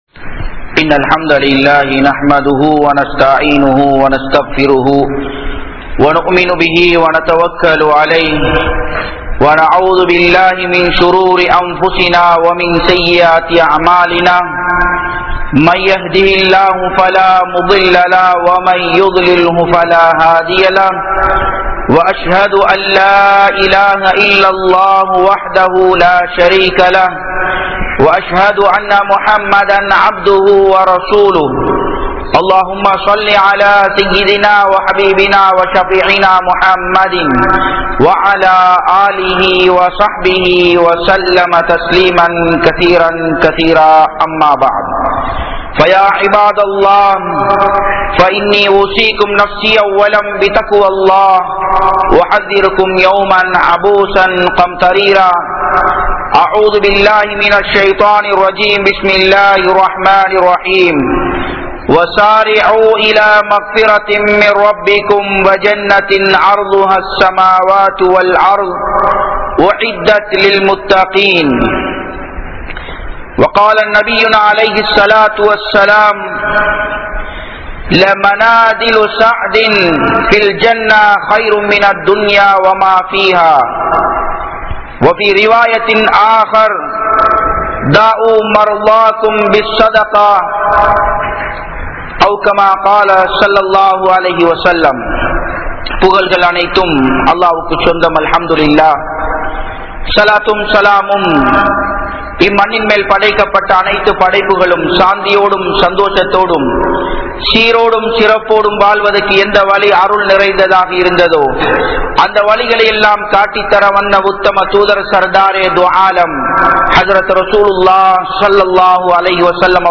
Suvarkam Yaarukkuriyathu? (சுவர்க்கம் யாருக்குரியது?) | Audio Bayans | All Ceylon Muslim Youth Community | Addalaichenai